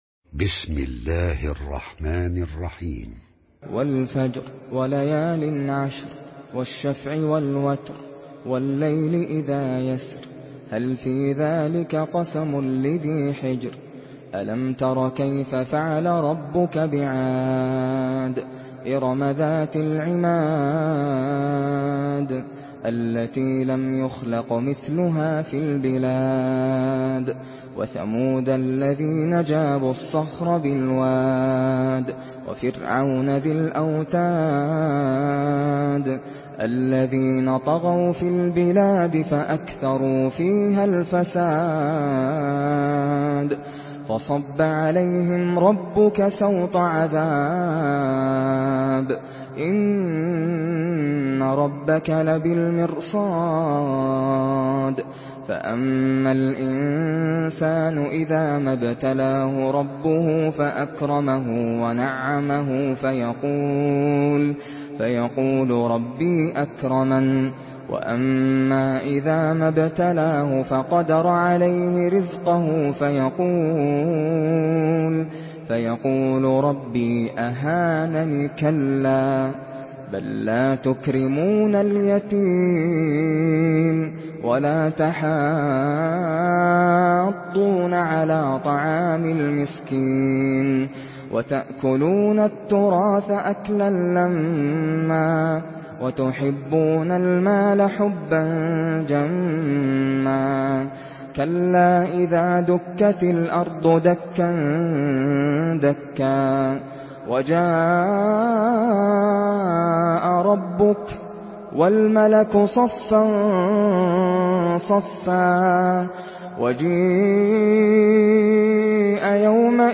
Surah Al-Fajr MP3 in the Voice of Nasser Al Qatami in Hafs Narration
Murattal Hafs An Asim